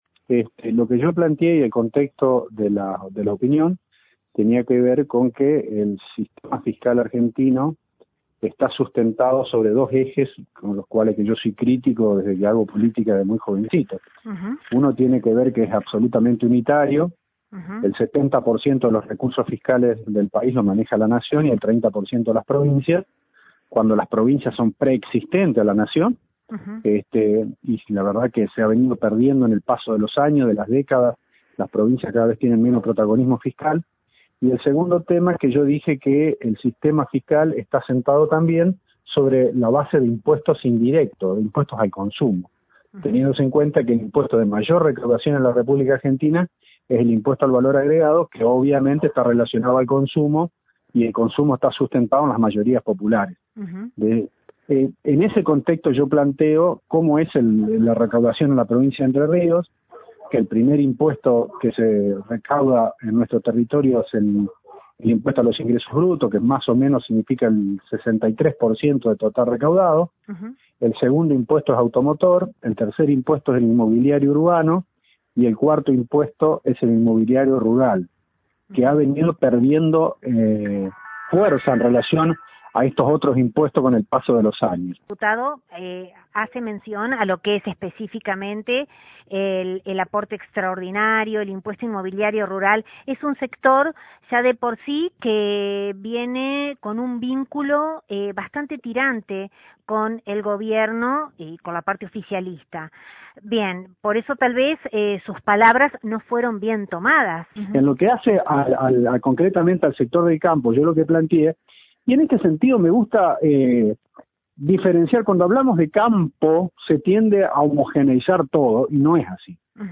Prima facie, Loggio en diálogo con nuestro medio expresó, “nunca dije eso…dije que ponía en discusión una realidad, hay un dato de la realidad que digo, discutámoslo”, en clara referencia al impuesto inmobiliario rural.